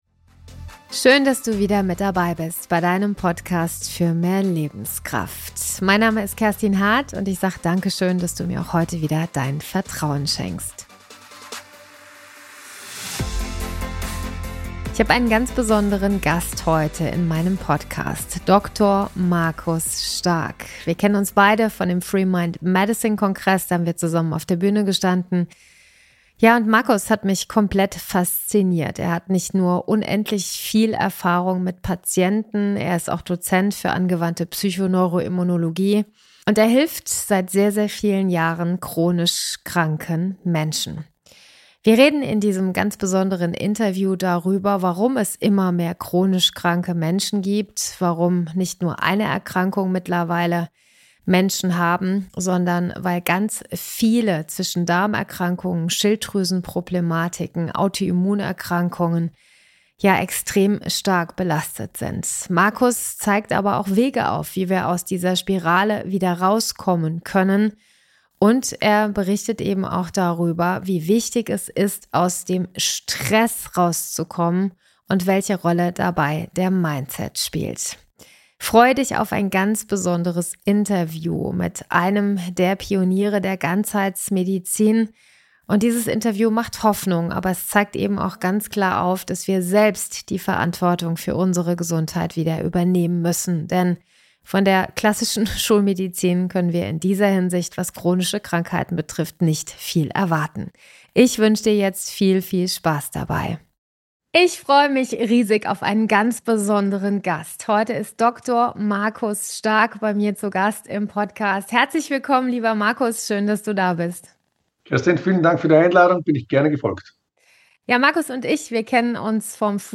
Ein tiefgehendes, sehr ehrliches Interview voller Wissen, Erfahrung und neuer Perspektiven.